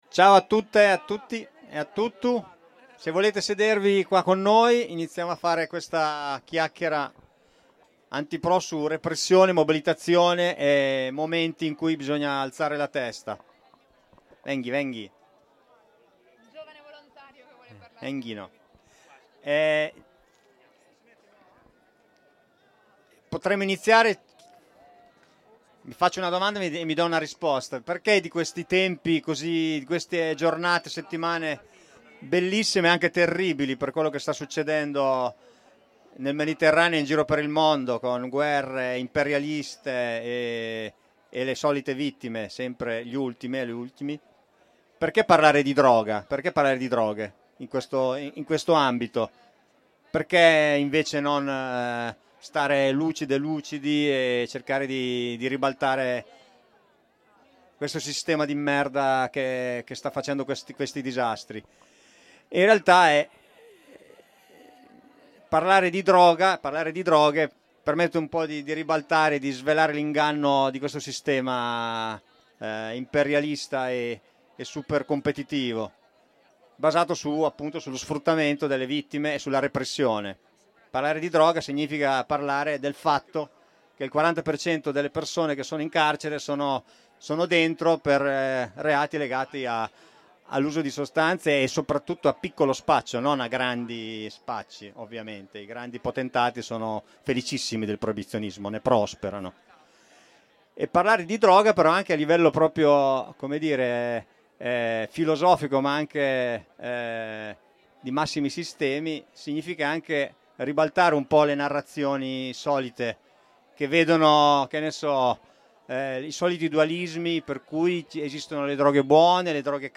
La registrazione della chiaccherata antipro in occasione dell’edizione speciale dei due anni della Rassegna autogestista “Senza Chiedere Permesso” a Bologna.